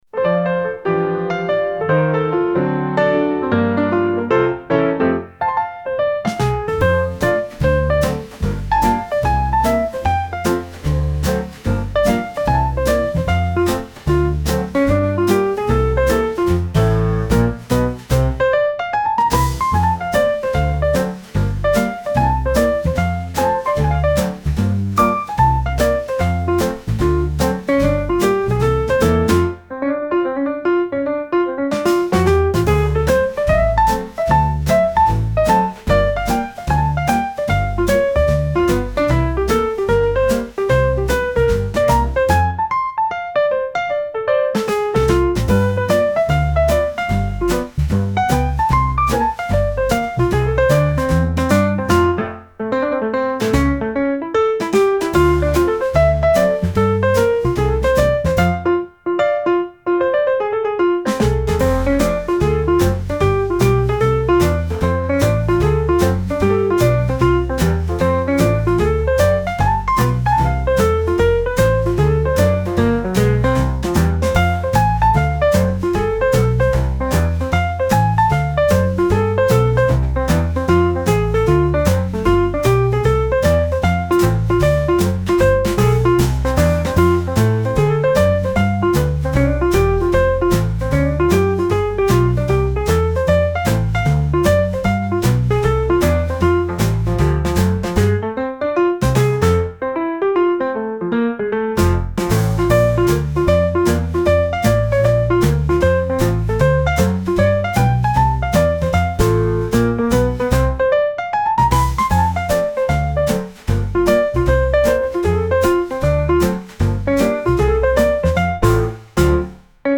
A mainstay of Melbourne’s soul scene since the 80s
With a voice like velvet and a trumpet that tells stories